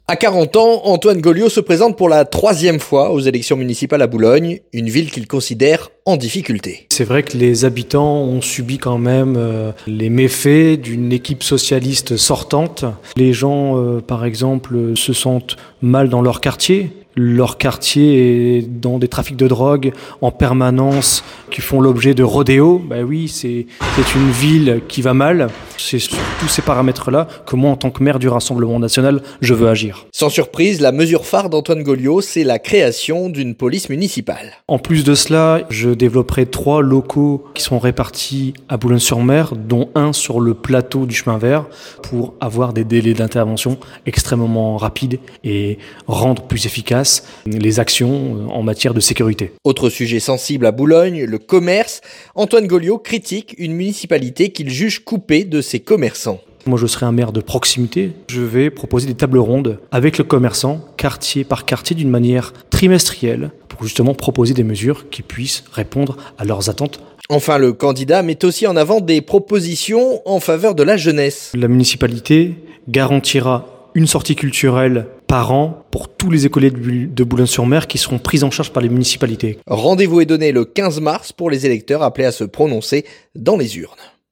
ENTRETIEN
Entretien.